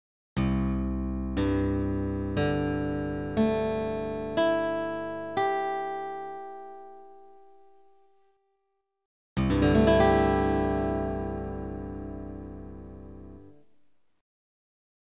새로운 표준 튜닝의 개방현